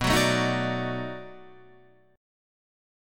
B7#9 Chord